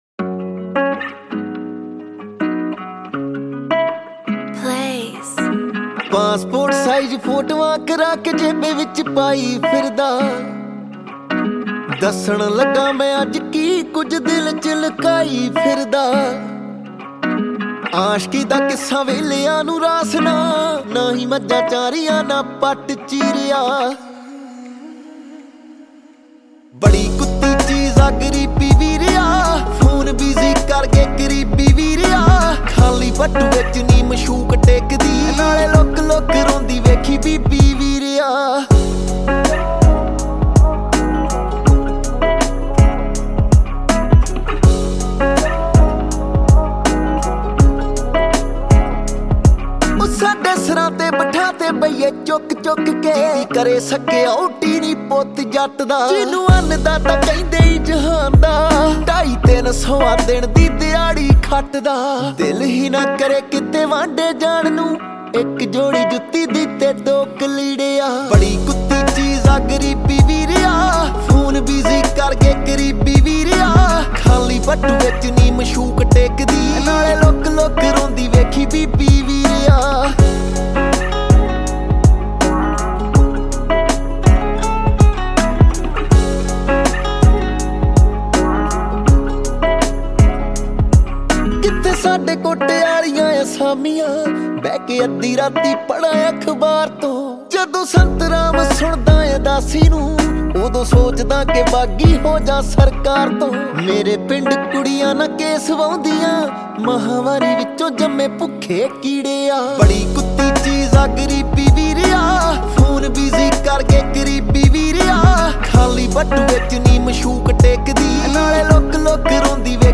Punjabi